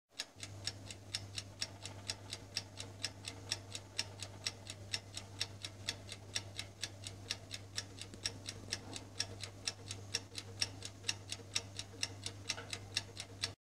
timer.ogg